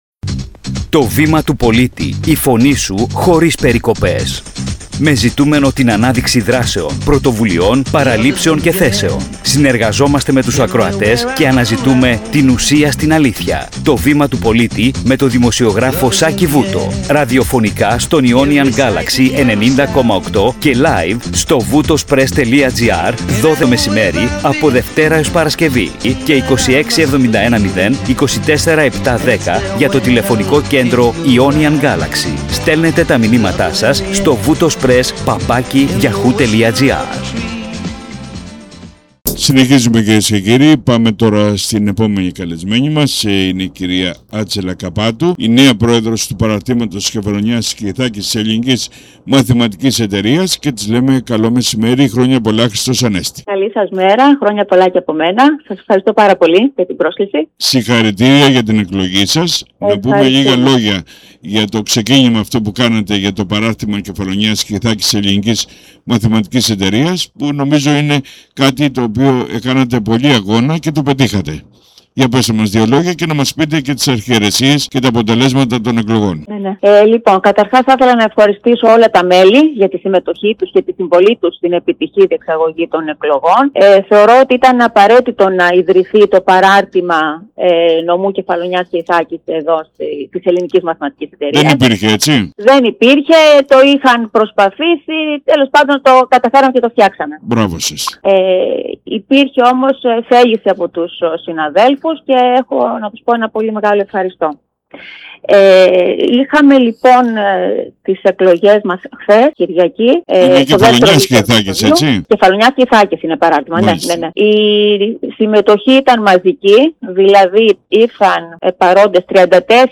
🟢 Κύρια θέματα της συνέντευξης